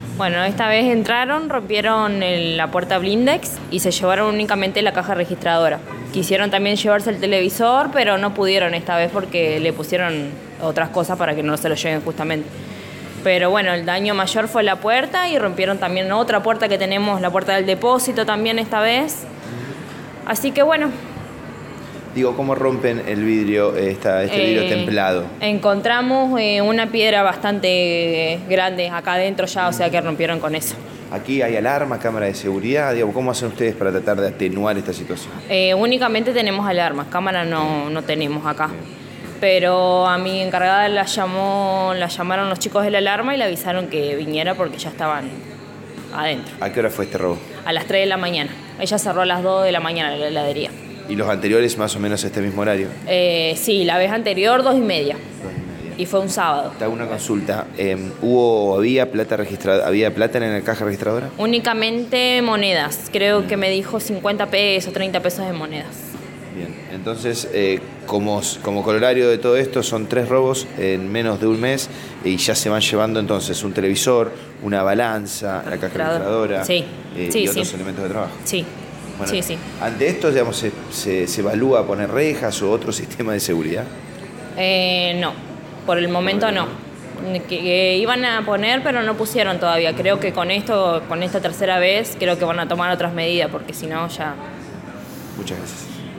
Una empleada de la reconocida firma que sufrió el robo comentó en los micrófonos de Radio EME que los ladrones rompieron la puerta blindex con un cascote y se llevaron la caja registradora.